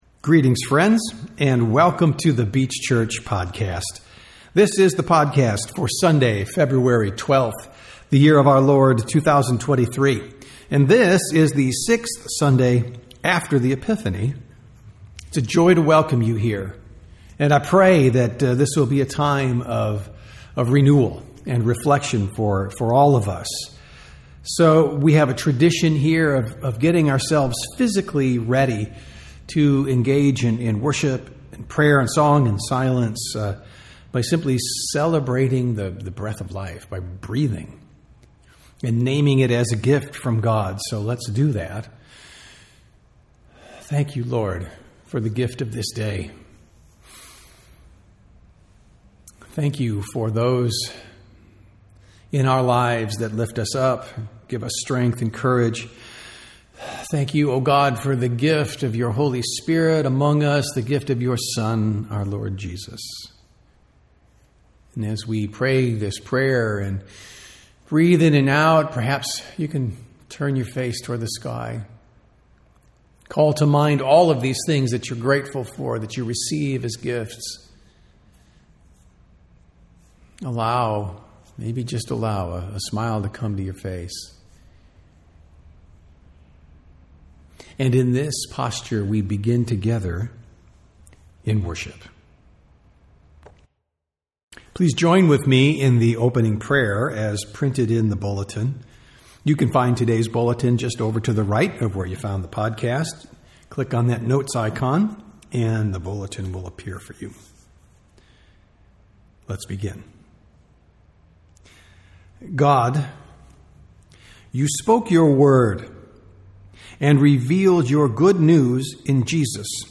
Sermons | The Beach Church
Sunday Worship - February 12, 2023